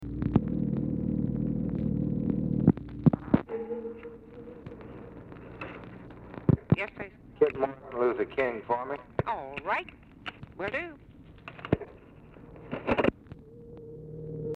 Telephone conversation # 701, sound recording, LBJ and TELEPHONE OPERATOR, 12/23/1963, time unknown | Discover LBJ
Dictation belt
Oval Office or unknown location